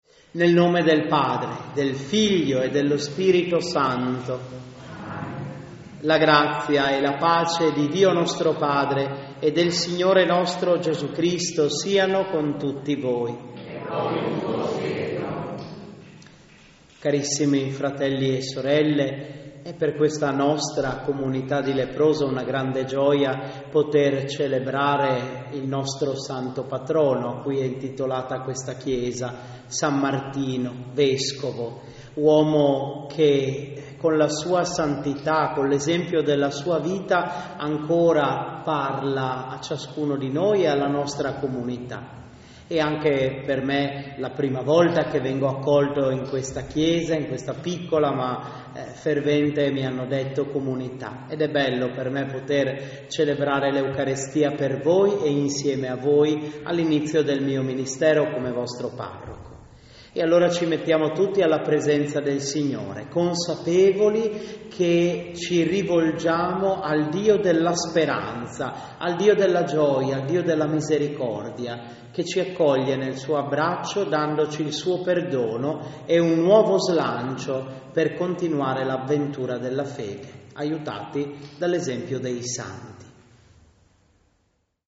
Festa Patronale di San Martino
CANTO E PREGHIERE DI APERTURA